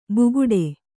♪ buguḍe